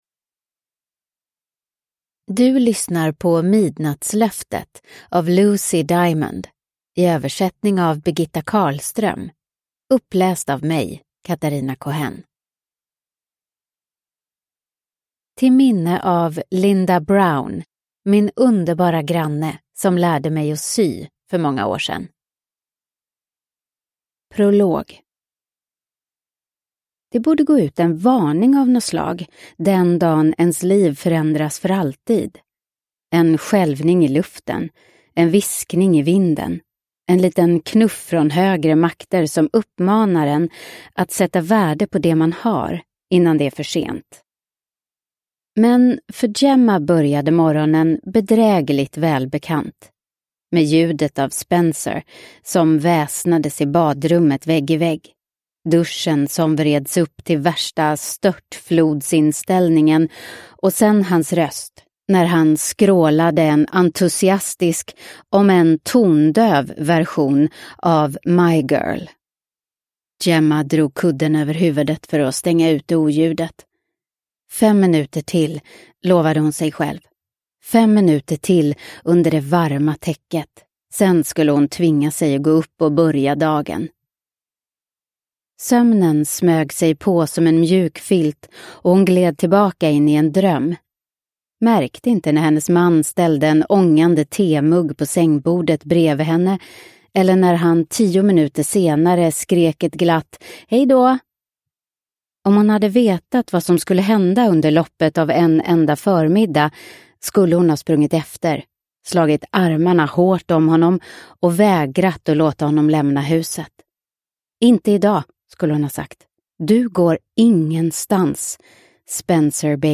Midnattslöftet – Ljudbok – Laddas ner